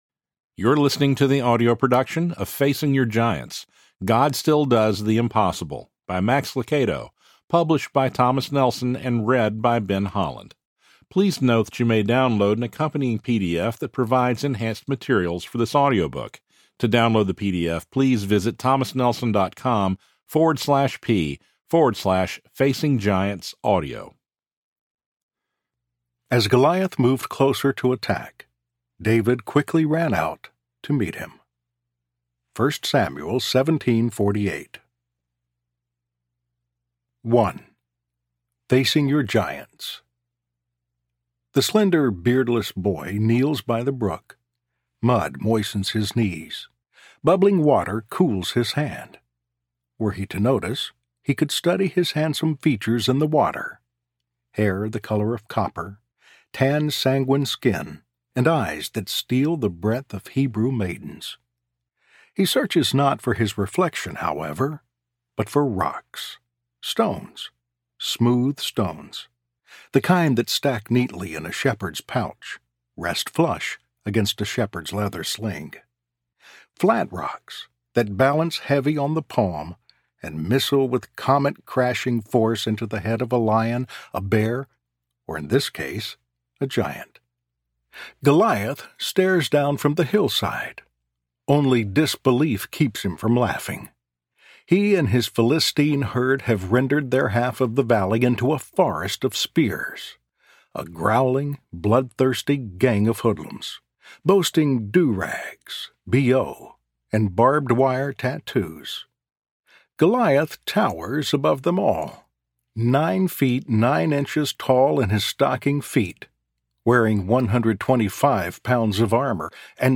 Facing Your Giants Audiobook
Narrator